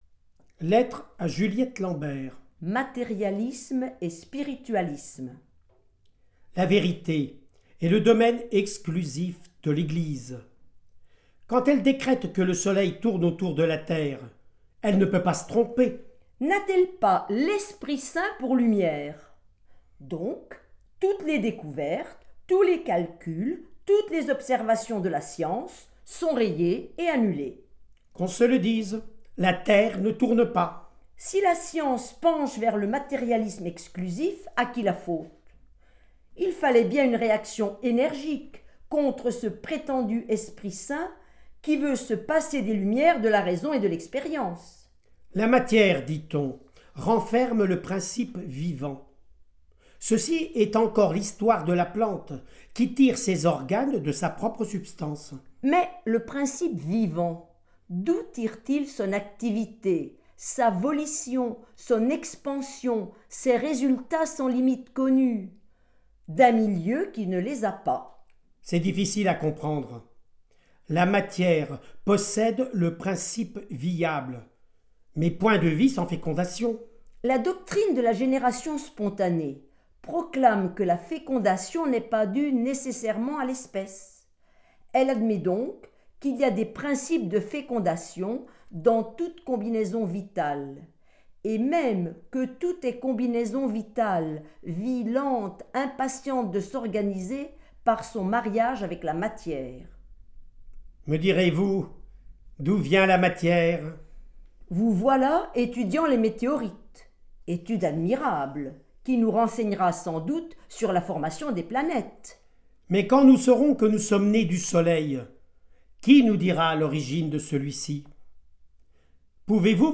• Lectures des textes de George Sand sur les Sciences, réalisées à l’occasion du colloque George Sand et les sciences et vie de la terre et de l’exposition au Muséum d’histoire naturelle de Bourges